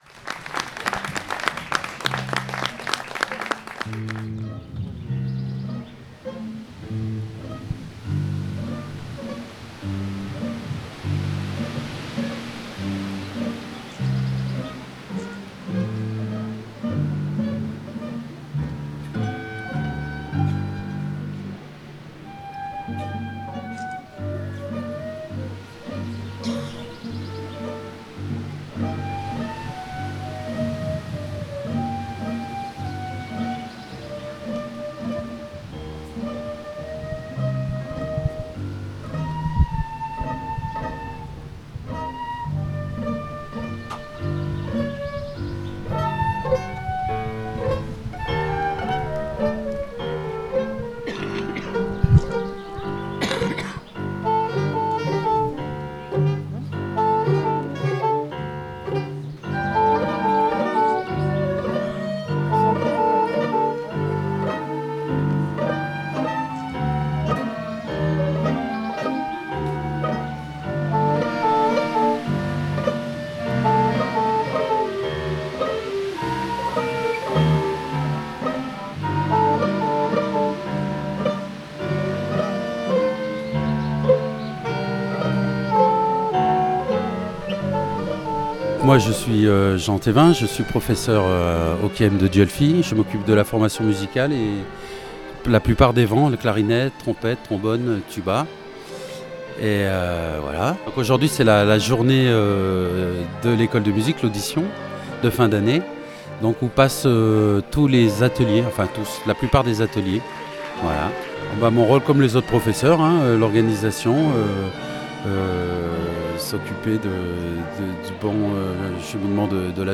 15 juillet 2017 11:46 | Interview, reportage
Le 2 Juillet 2017 s’est tenu la fête du CAEM. Tous les élèves de 3 à 80 ans se sont produits devant l’école. une journée riche en musique !